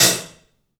Index of /90_sSampleCDs/E-MU Producer Series Vol. 5 – 3-D Audio Collection/3DPercussives/3DPAHat